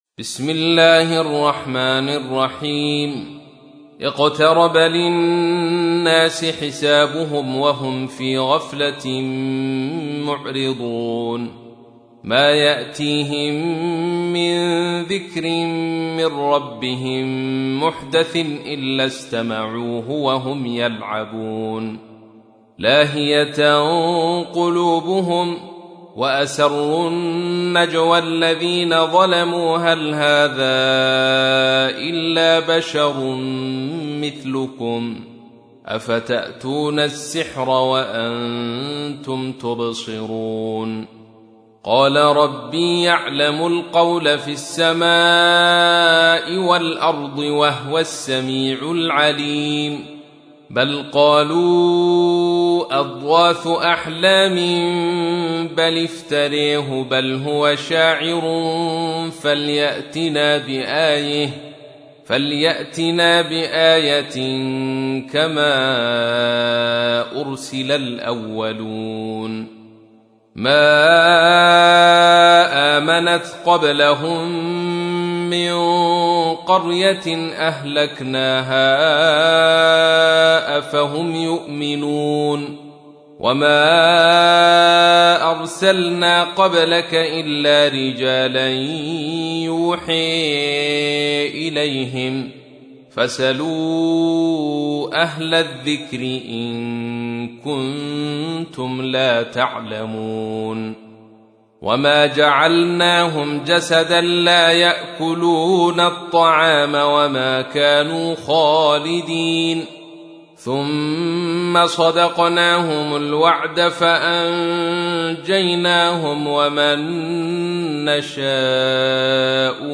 تحميل : 21. سورة الأنبياء / القارئ عبد الرشيد صوفي / القرآن الكريم / موقع يا حسين